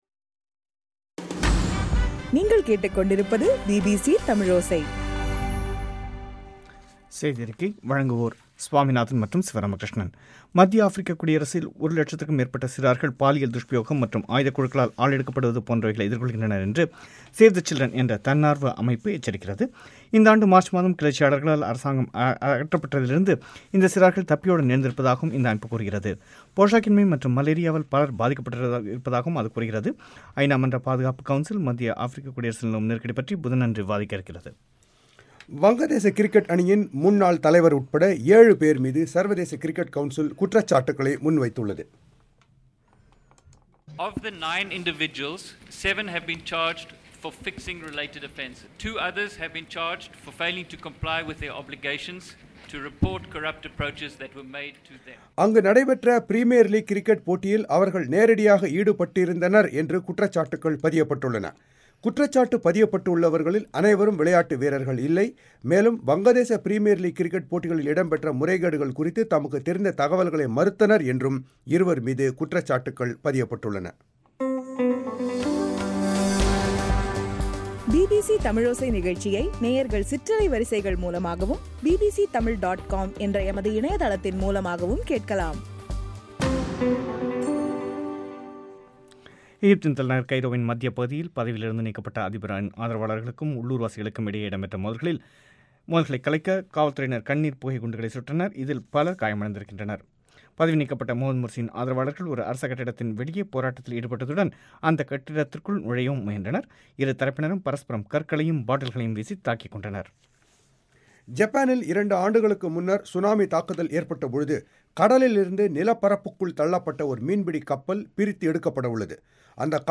இலங்கையின் சக்தி வானொலிக்கான உலகச் செய்தியறிக்கை. 13/08/2013